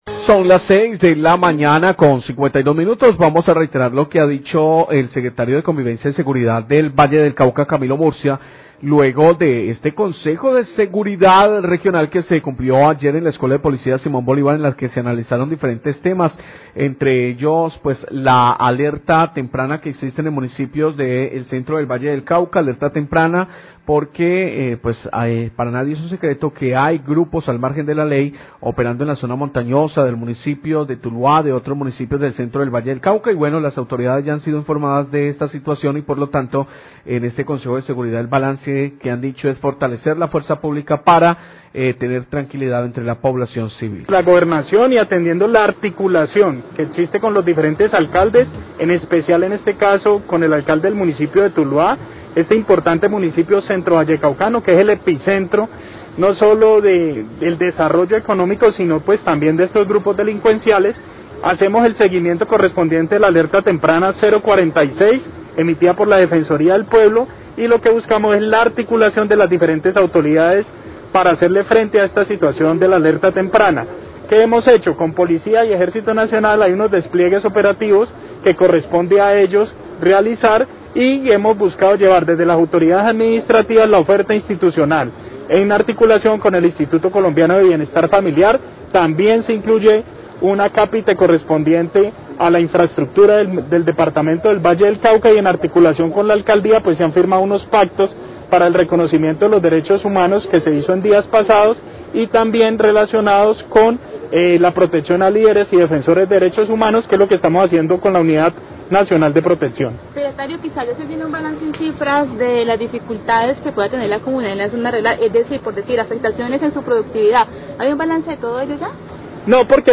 Srio Seguridad Valle habla del consejo de seguridad por conflicto armado en centro del Valle
Radio